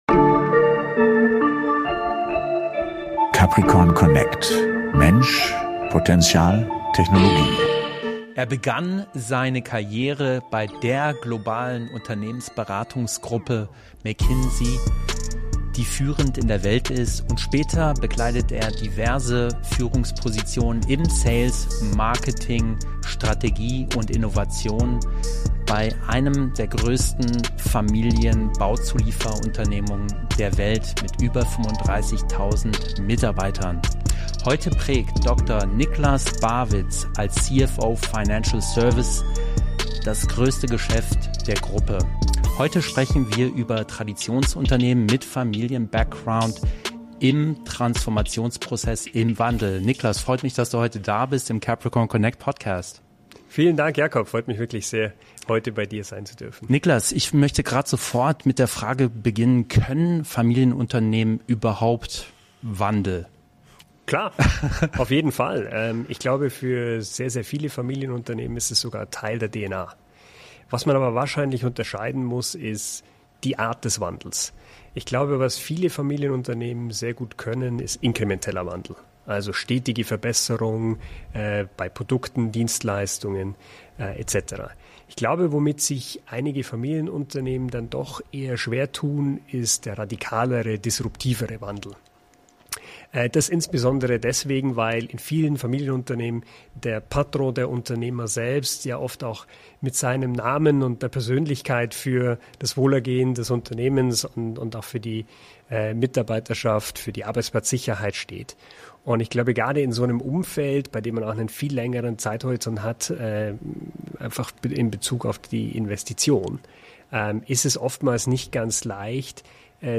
Ein Gespräch über Verantwortung, Veränderung und den Mut, Zukunft aktiv zu gestalten – mit vielen Impulsen für Führungskräfte, Innovatoren und alle, die sich mit Change in etablierten Organisationen beschäftigen.